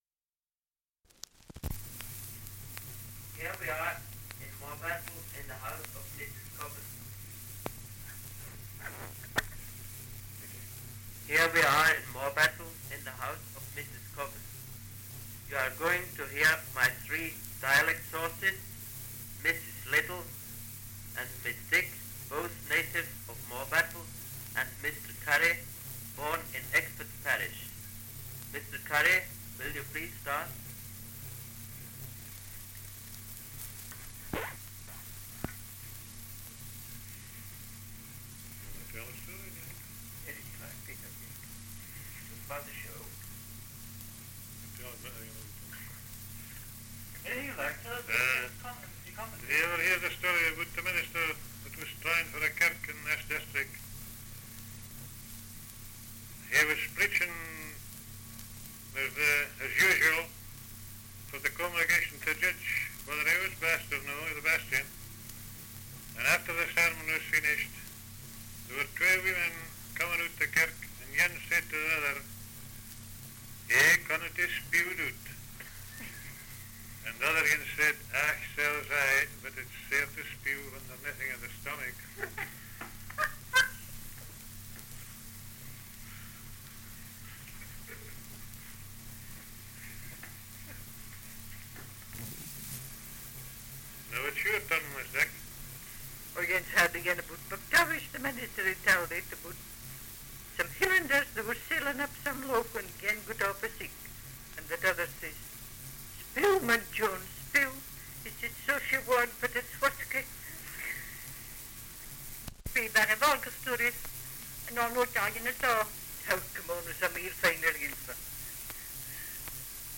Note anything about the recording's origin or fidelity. Dialect recording in Morebattle, Roxburghshire 78 r.p.m., cellulose nitrate on aluminium